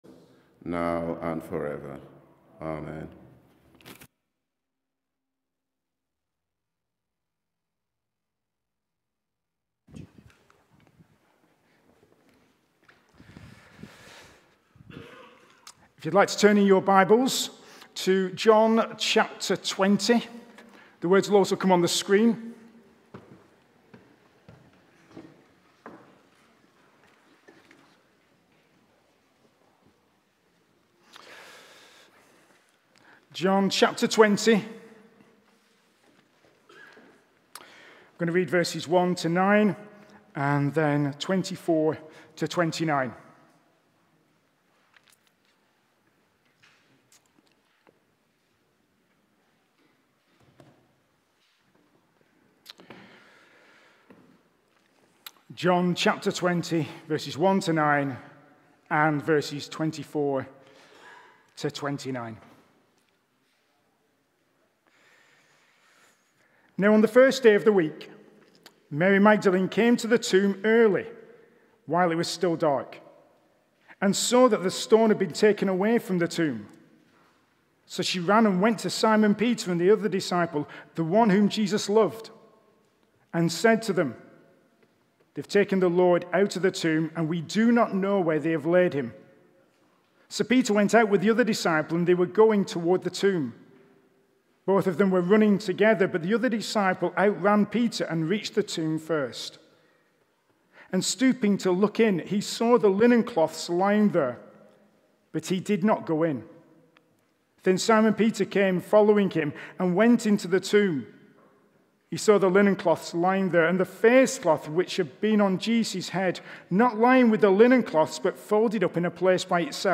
One Offs, Easter Sunday Sermon